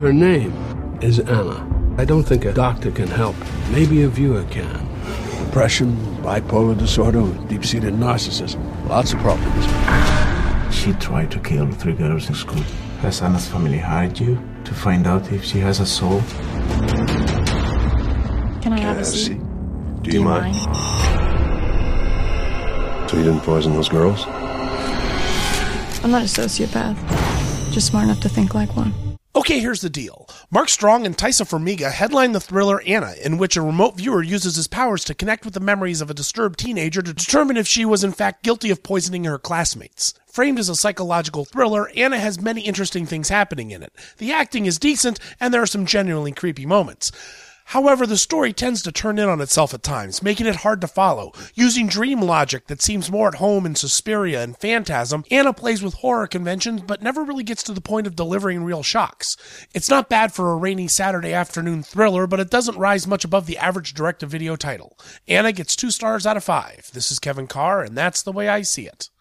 ‘Anna’ Movie Review